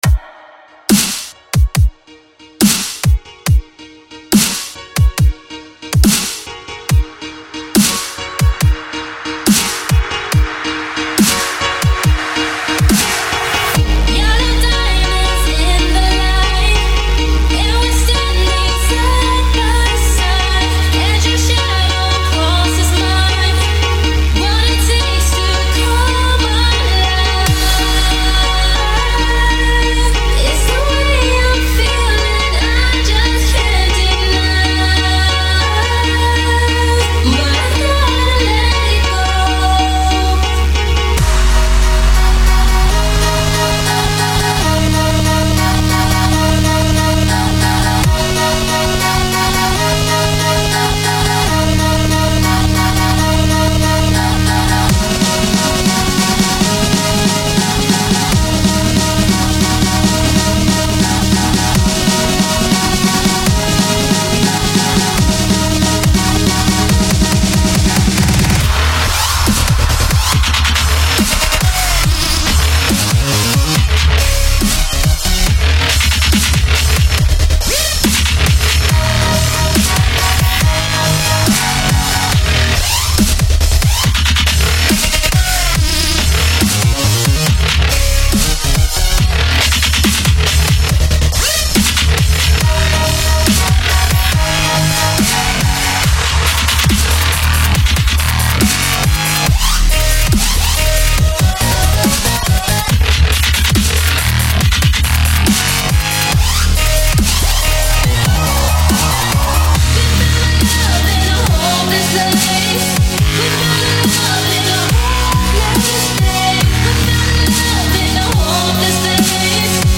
DRUM & BASS [3]
Категория: DUB STEP-->